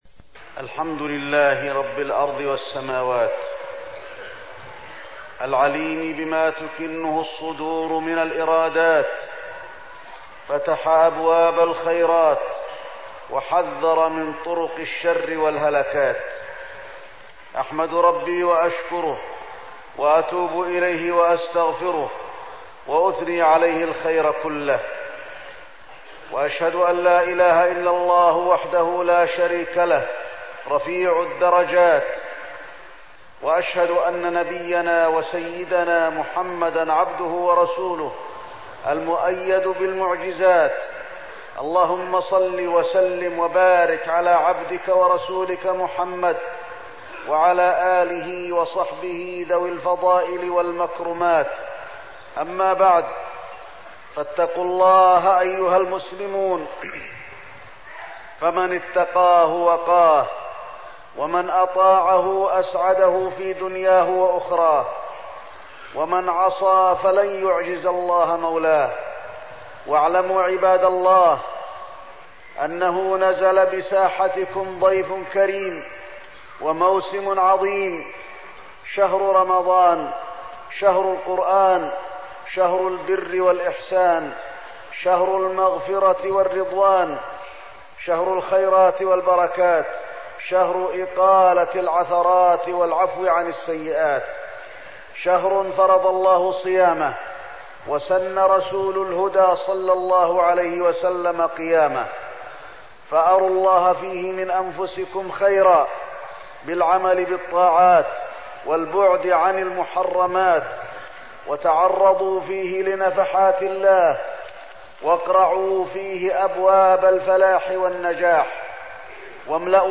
تاريخ النشر ١ رمضان ١٤١٧ هـ المكان: المسجد النبوي الشيخ: فضيلة الشيخ د. علي بن عبدالرحمن الحذيفي فضيلة الشيخ د. علي بن عبدالرحمن الحذيفي فضل شهر رمضان